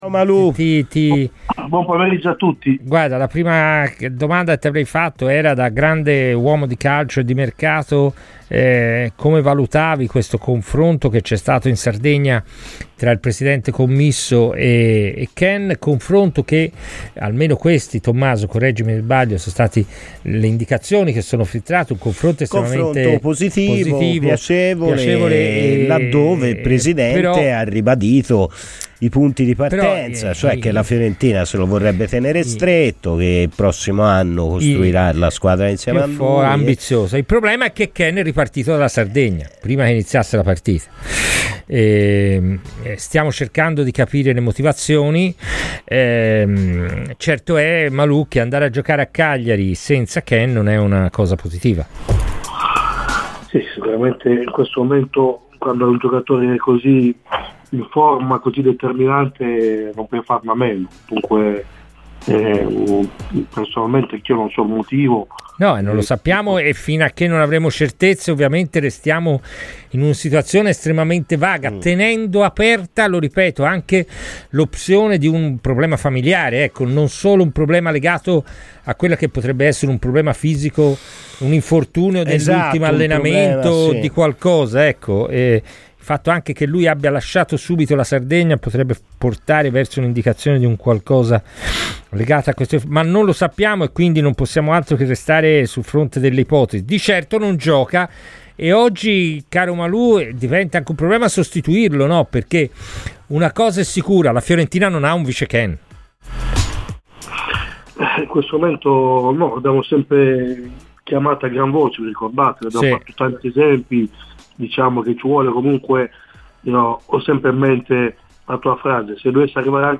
Clicca sul podcast per l'intervista integrale